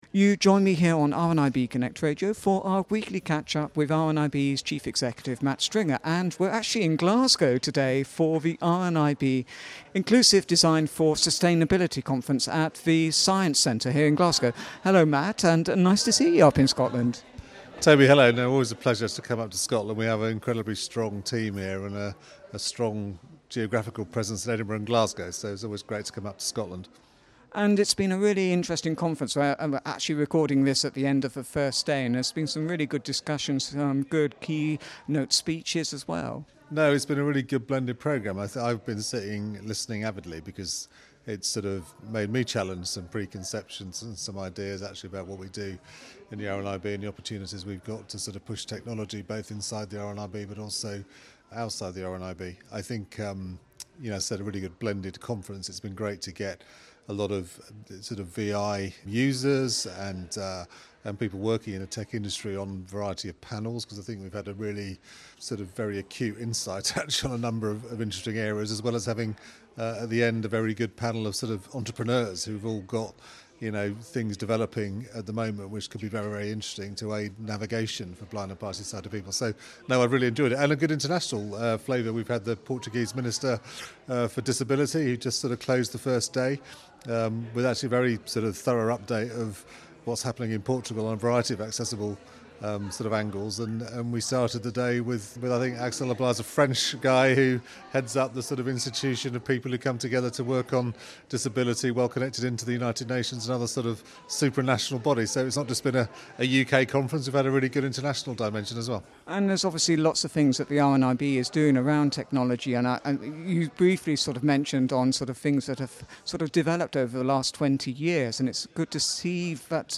This week’s update was recorded during the RNIB Scotland, inclusive Design for Sustainability conference held at the Glasgow Science Centre 21 / 22 June 2023.